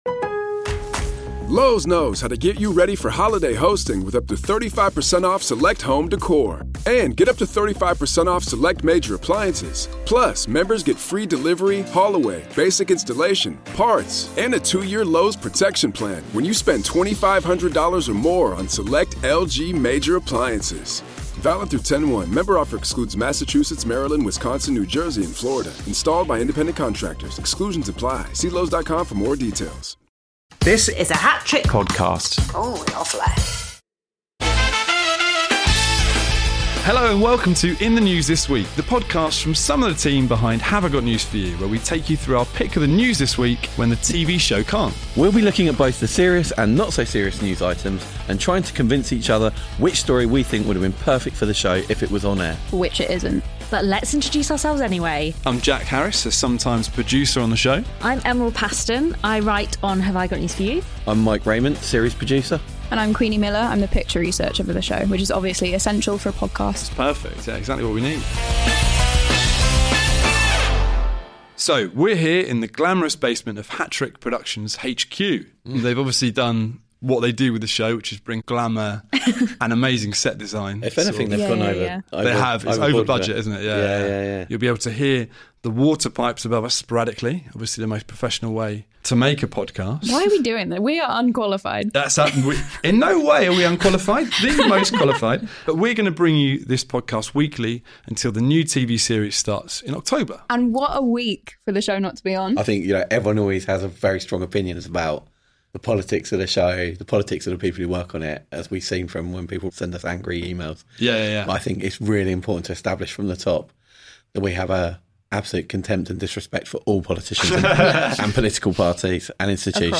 Join some of the team behind Have I Got News For You as they take you through the serious (but more often not-so-serious) stories In The News This Week.